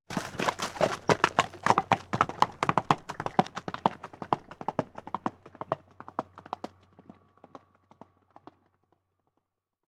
Caballo saliendo al galope sobre tierra 2